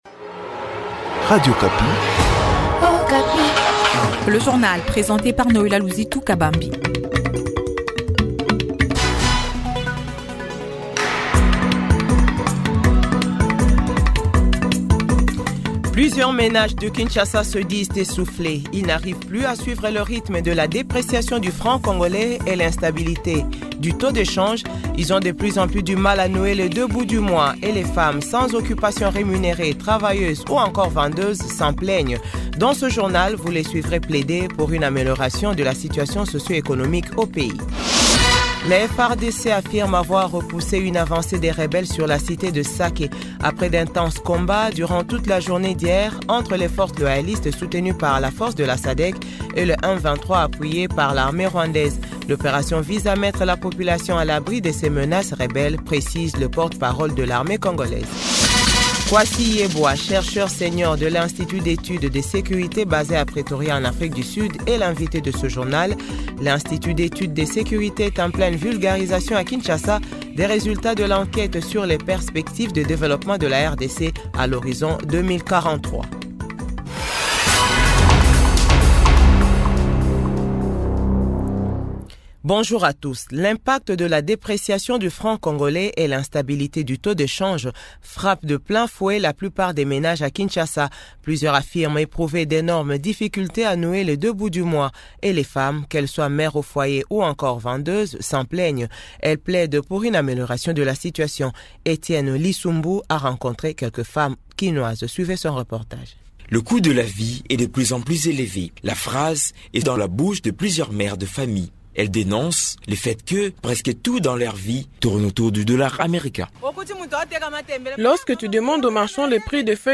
JOURNAL FRANÇAIS 6H00-7H00